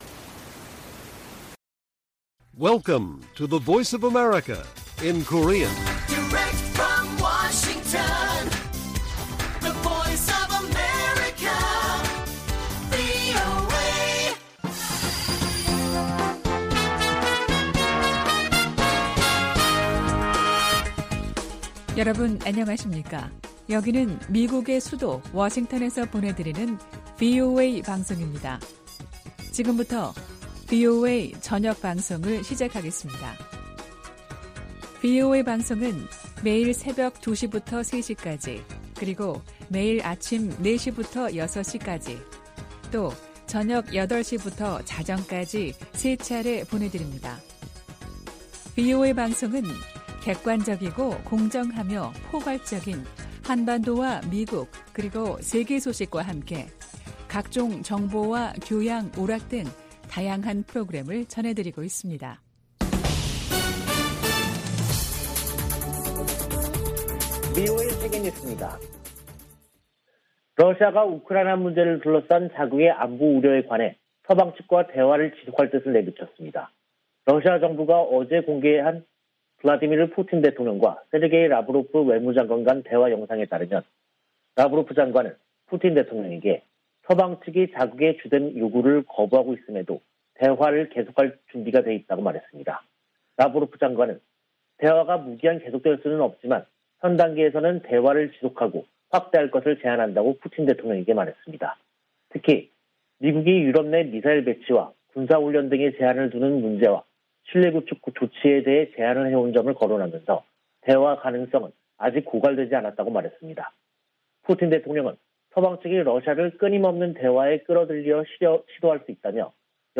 VOA 한국어 간판 뉴스 프로그램 '뉴스 투데이', 2022년 2월 15일 1부 방송입니다. 미 국무부는 북한 영변 핵 시설이 가동 중이라는 보도에 대해 북한이 비확산 체제를 위협하고 있다고 비판했습니다. 조 바이든 미국 대통령이 물러날 때 쯤 북한이 65개의 핵무기를 보유할 수도 있다고 전문가가 지적했습니다. 미한일이 하와이에서 북한 문제를 논의한 것과 관련해 미국의 전문가들은 3국 공조 의지가 확인됐으나, 구체적인 대응이 나오지 않았다고 평가했습니다.